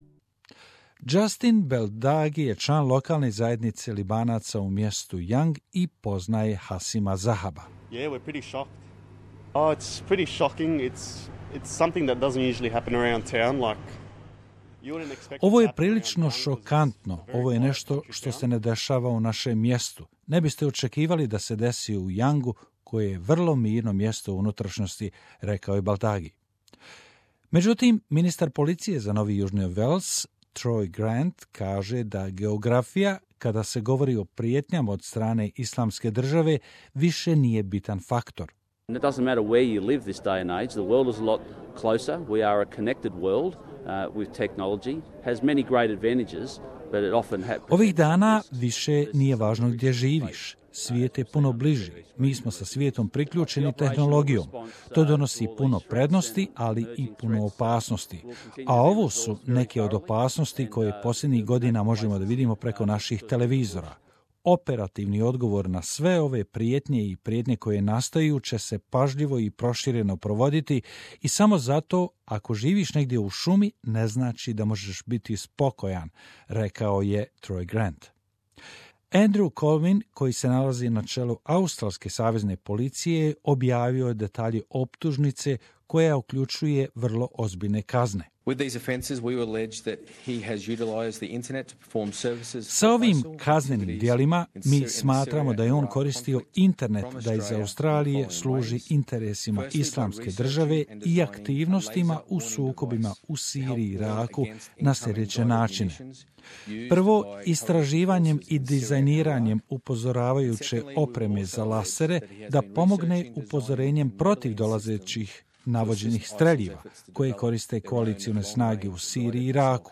There have been angry exchanges in parliament during Question Time after Queensland Independent MP Bob Katter called for the government to introduce a Trump-style ban on visas for people from certain countries.